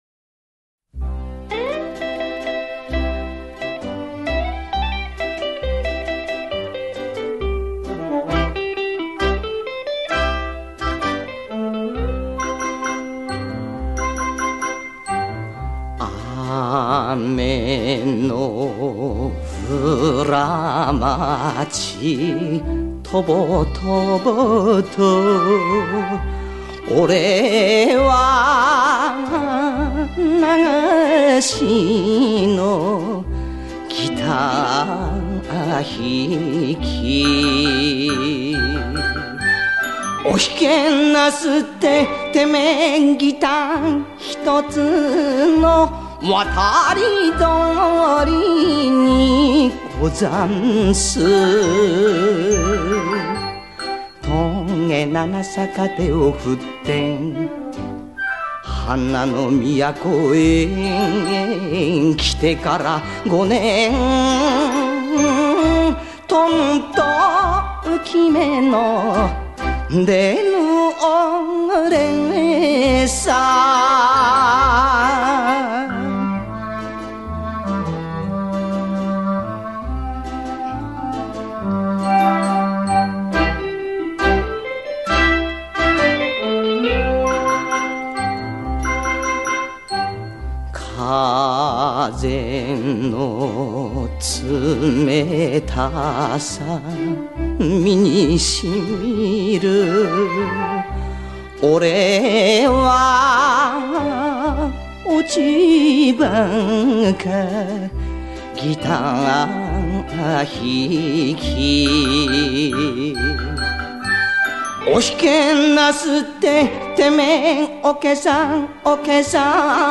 代流行曲的唱法熔为一炉，形成了自己独特的演唱技法。